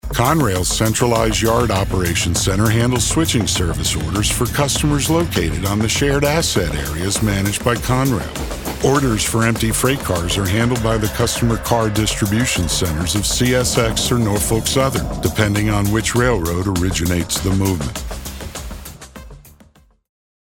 eLearning/Explainer/Audio Guides/
Whether it is a directed session, or done remotely in my home studio, we will execute the script on the background gained from the Planning stage..